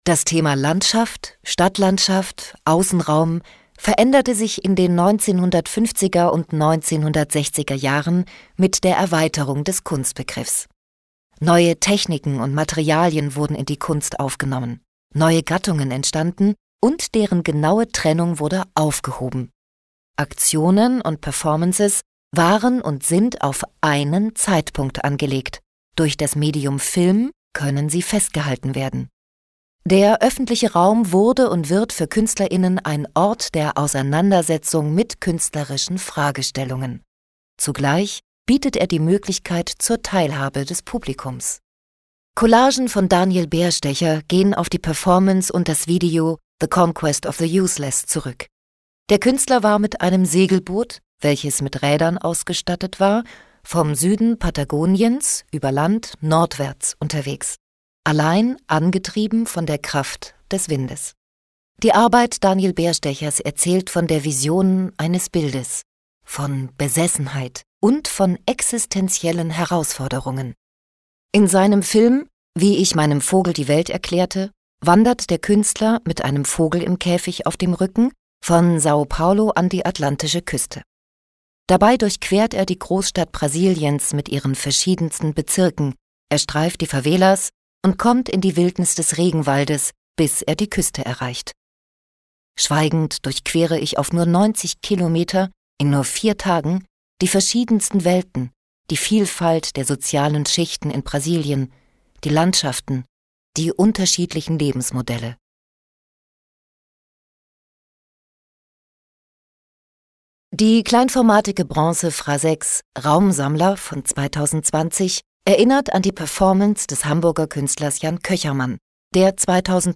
Audioguide 04 - Kunsthalle Göppingen: Kunst findet statt!